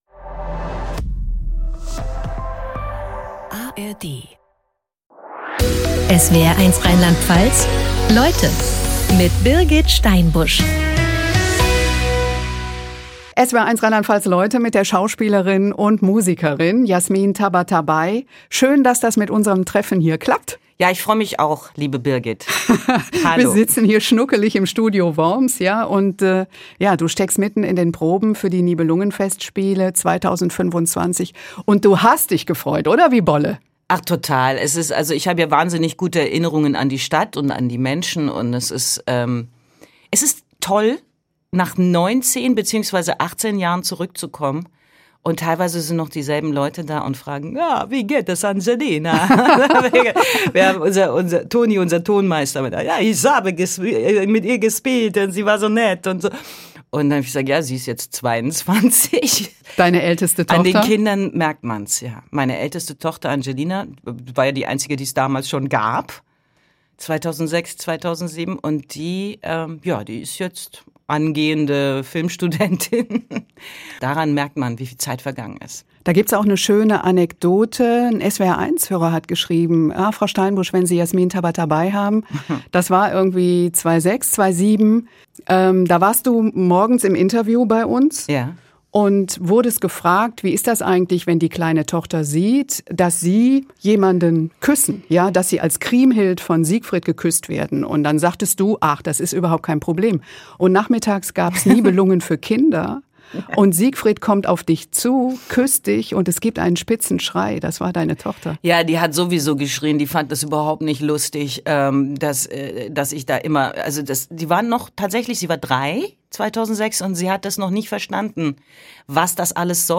Talks mit besonderen Menschen und ihren fesselnden Lebensgeschichten aus Politik, Sport, Wirtschaft oder Wissenschaft.